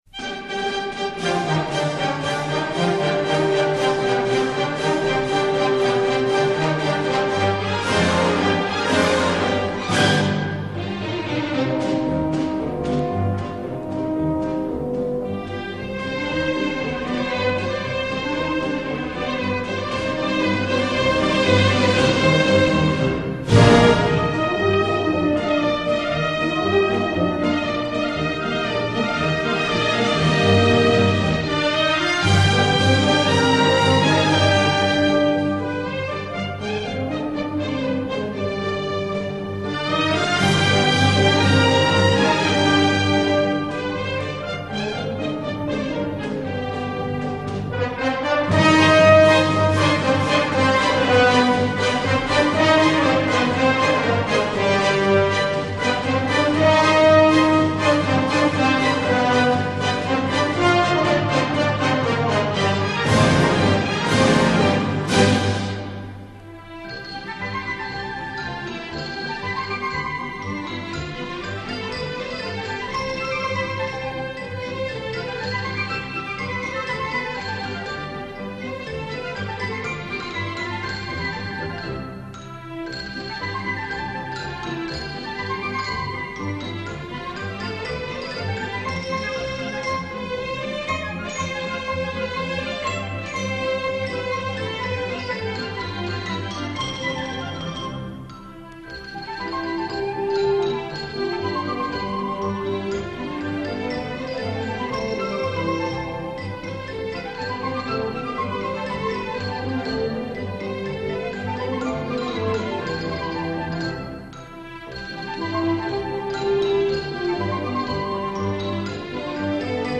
Spanish march
Spanish march ~1600 (Baroque, Classical) Spain Group: March Influenced: Paso doble The most characteristic Spanish march form is the pasodoble. Spanish marches often have fanfares at the beginning or end of strains that are reminiscent of traditional bullfight music. These marches often move back and forth between major and (relative) minor keys, and often show a great variation in tempo during the course of the march reminiscent of a prolonged Viennese rubato.